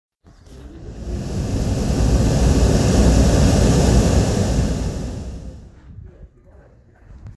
An den Klippen sitzen Angler, der Atlantik tost und gischtet aus jeder Perspektive und besonders durch die umzäunten Löcher und eine labyrinthartige Betonspirale, die mehrere Kalksteinlöcher umrundet. Der Schall, der dort heraufbraust, bringt den Boden zum Wackeln und dringt einem durch Mark und Bein.